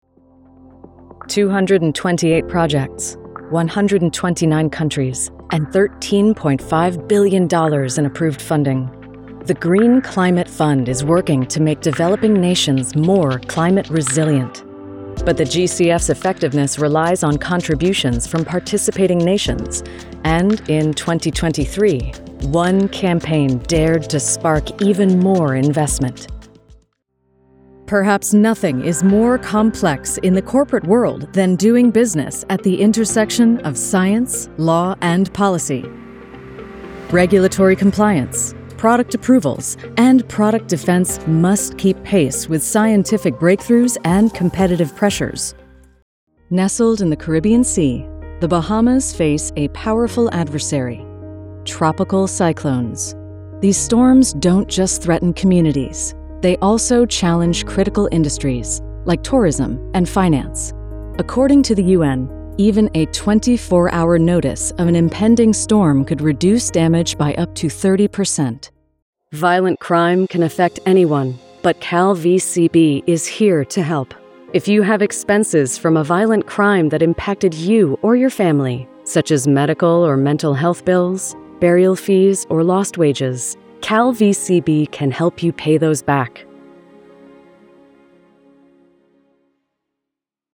Professional Female Voiceover Artist